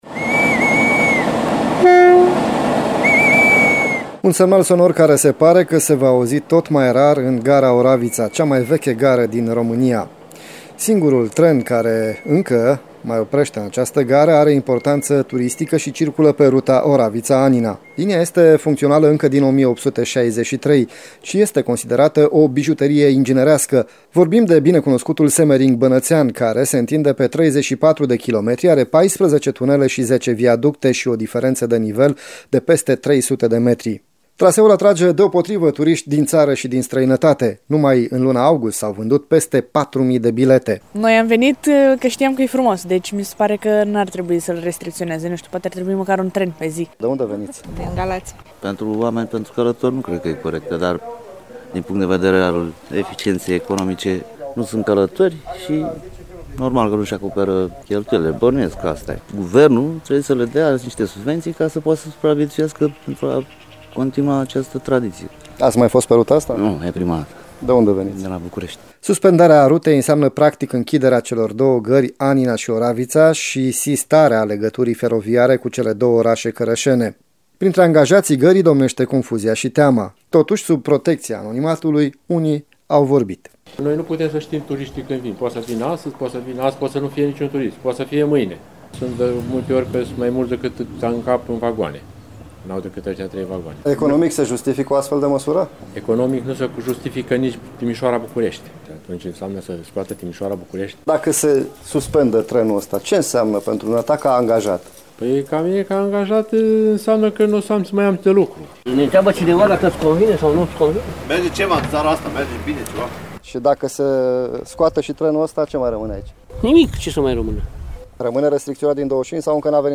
Sub protecția anonimatului, unii oameni au fost de acord să vorbească.